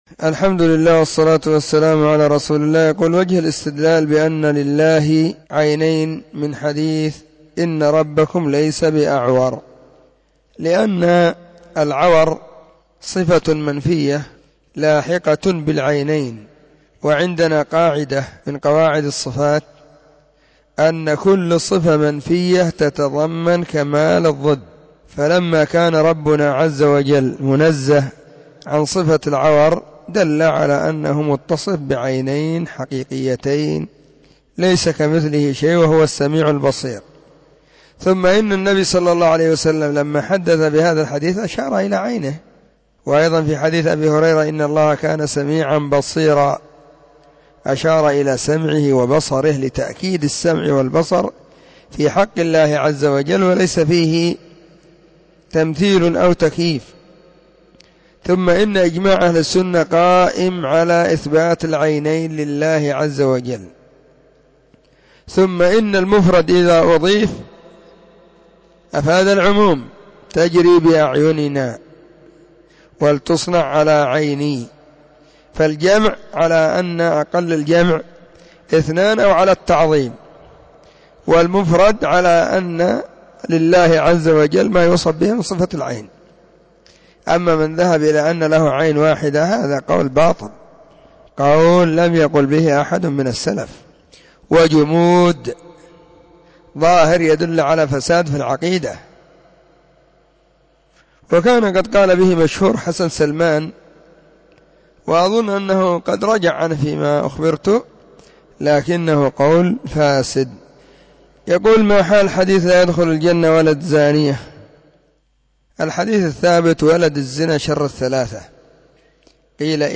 فتاوى ,الجمعة 30 /ربيع الأول/ 1443 هجرية., أسئلة -6